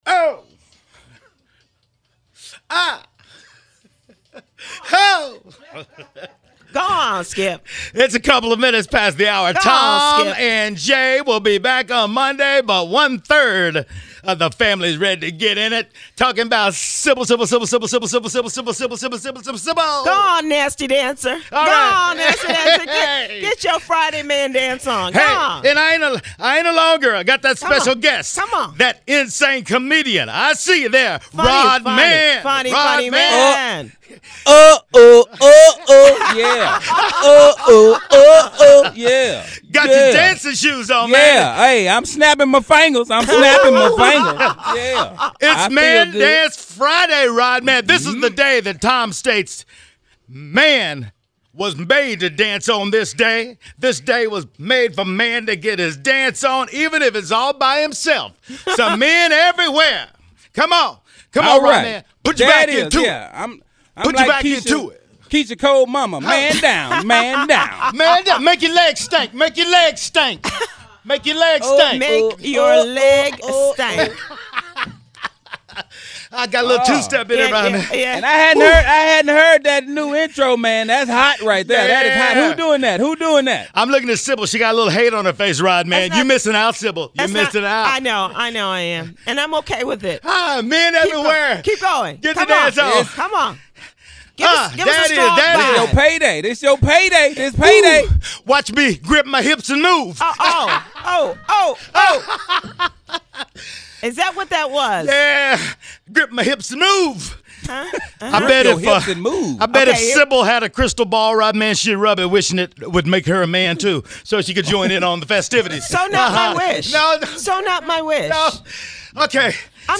TOP OF THE MORNING: Comedian Rod Man Co-Hosts The TJMS!